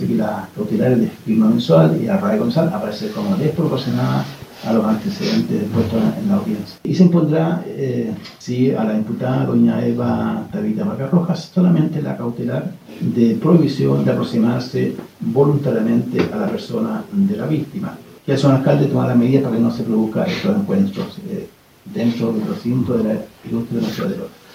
Por ello, sólo se decretó la medida de prohibición de acercarse voluntariamente a la afectada, ante lo cual el municipio deberá aplicar medidas que eviten el encuentro entre las partes, dado que se trata de dos funcionarias públicas, dijo el juez Jorge Henríquez.